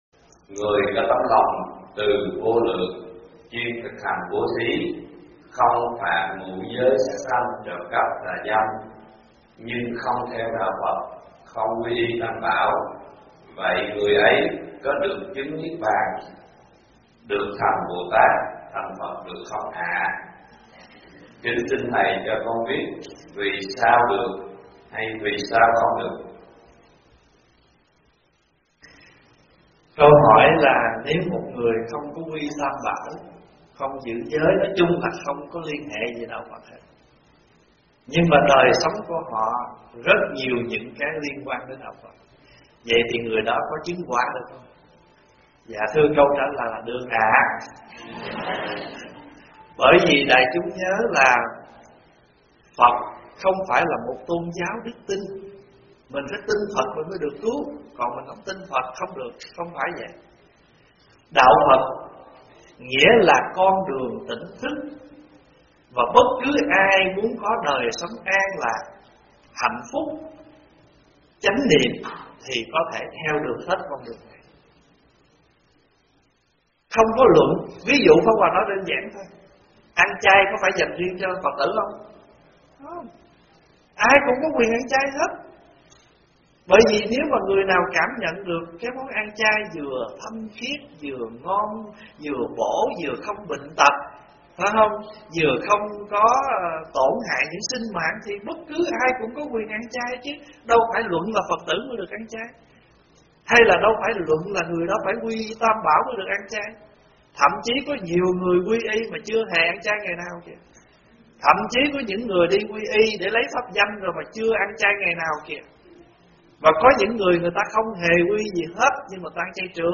Mời quý phật tử nghe vấn đáp Người không phải Đạo Phật có thể Chứng Quả không? - ĐĐ. Thích Pháp Hòa giảng
Mp3 Thuyết Pháp     Thuyết Pháp Thích Pháp Hòa     Vấn đáp Phật Pháp